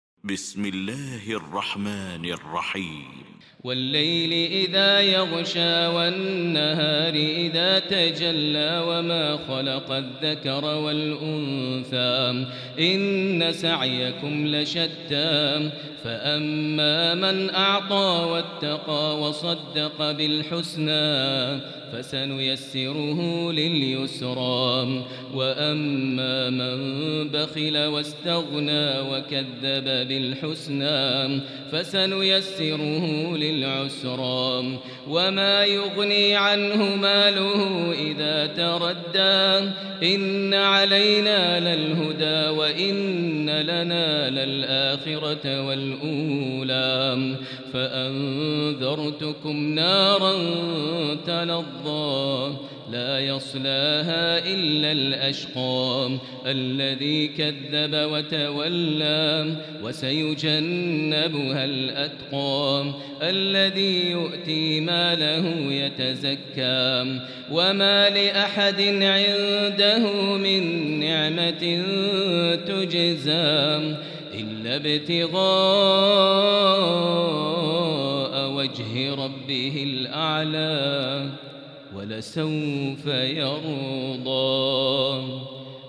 المكان: المسجد الحرام الشيخ: فضيلة الشيخ ماهر المعيقلي فضيلة الشيخ ماهر المعيقلي الليل